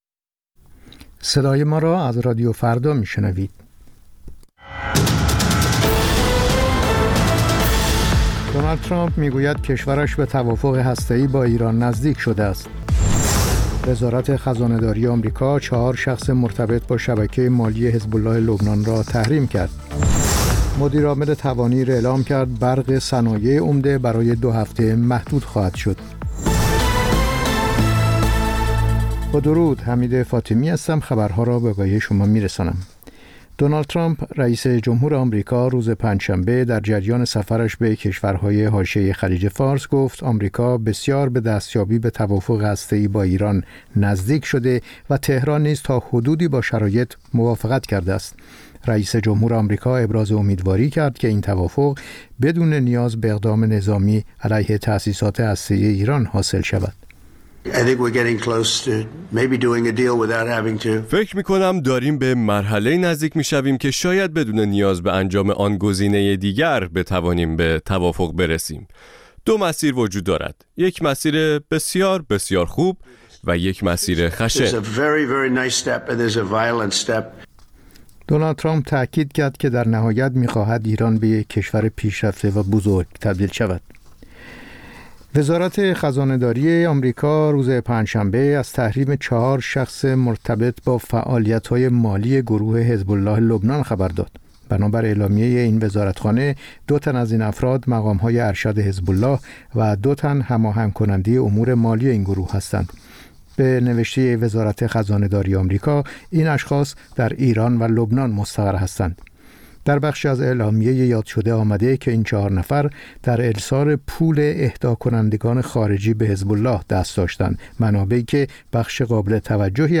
سرخط خبرها ۲۳:۰۰